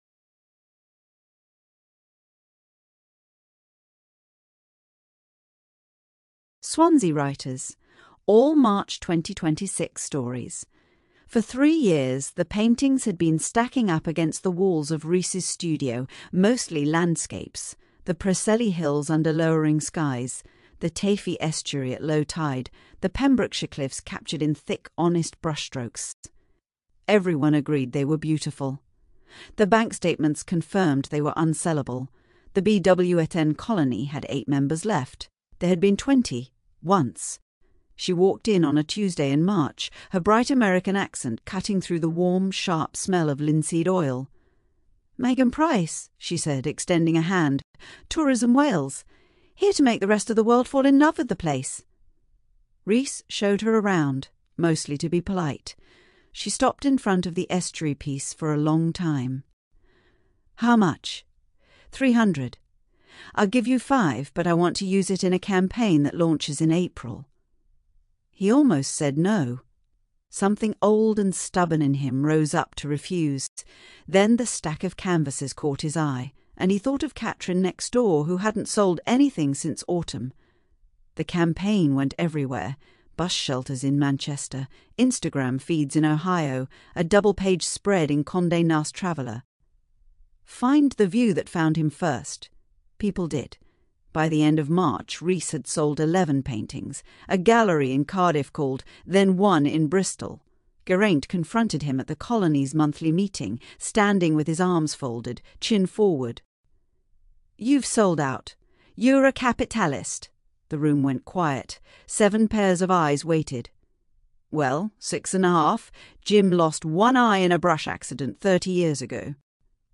Swansea Writers Audio Books
These are the collected audiobooks of our stories.